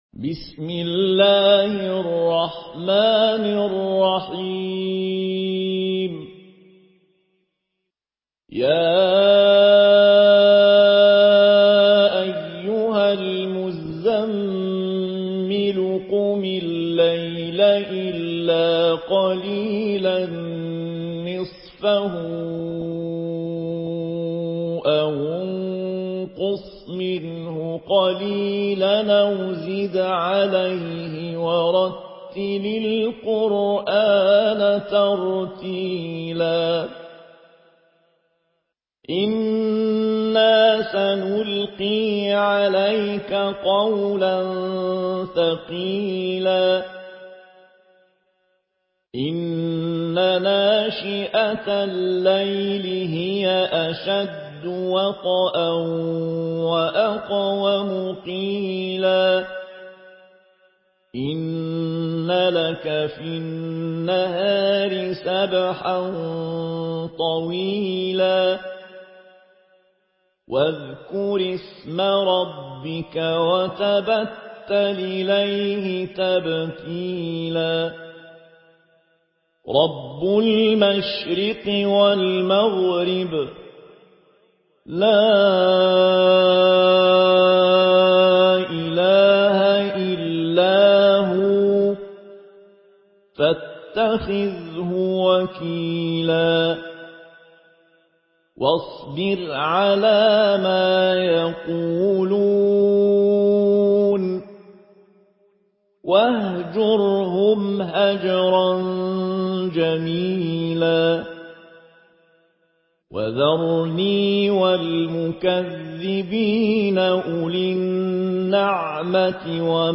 Murattal Warsh An Nafi